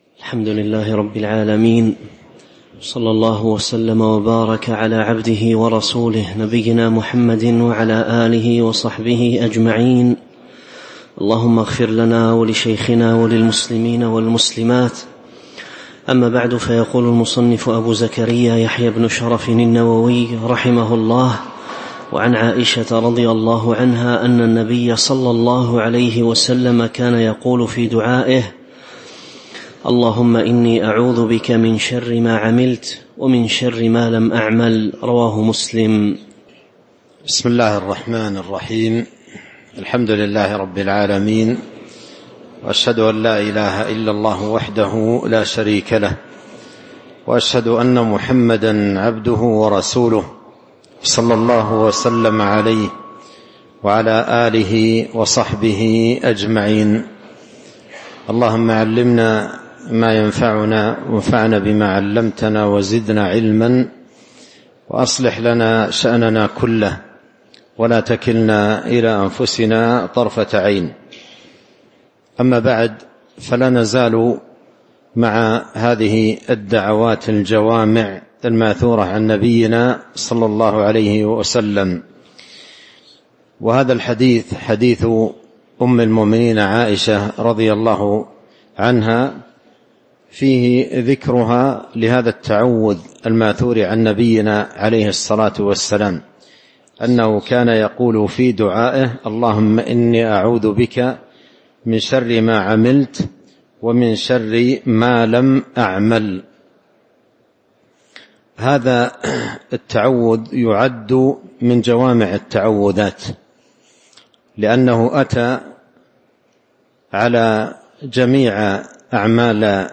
تاريخ النشر ٥ رمضان ١٤٤٥ هـ المكان: المسجد النبوي الشيخ: فضيلة الشيخ عبد الرزاق بن عبد المحسن البدر فضيلة الشيخ عبد الرزاق بن عبد المحسن البدر باب فضل الدعاء (05)